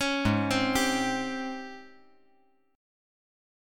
AbM11 Chord
Listen to AbM11 strummed